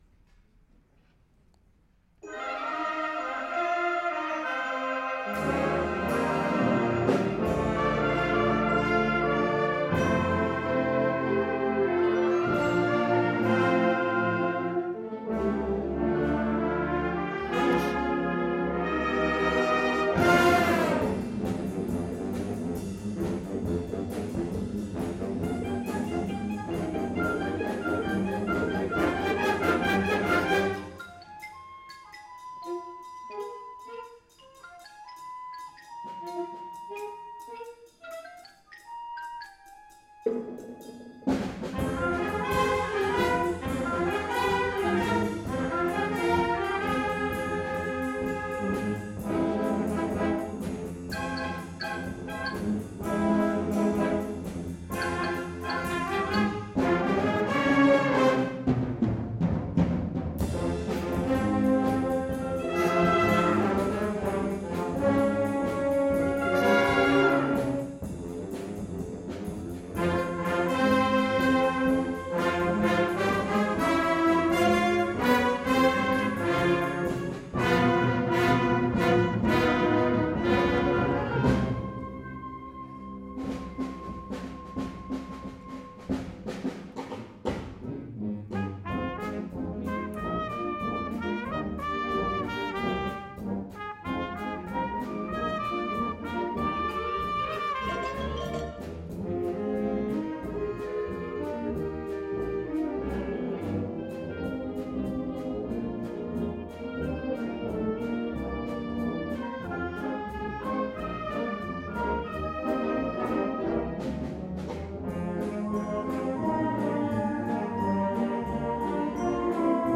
2014 Summer Concert